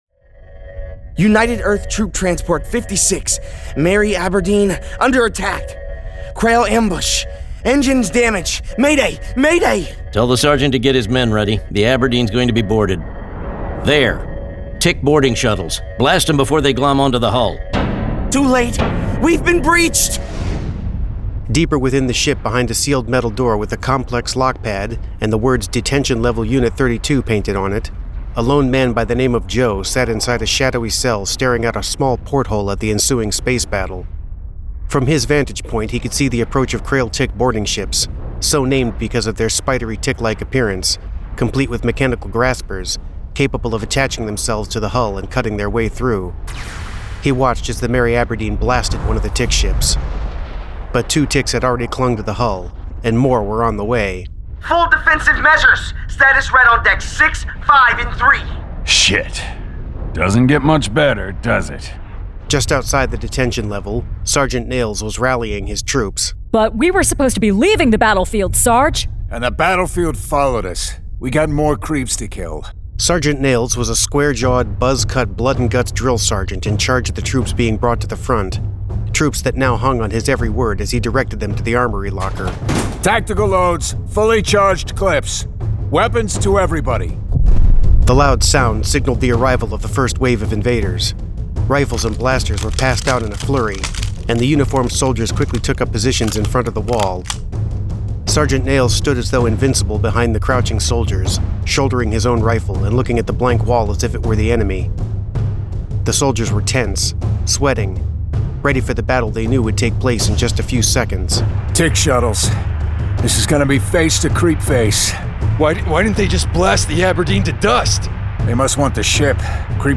Full Cast. Cinematic Music. Sound Effects.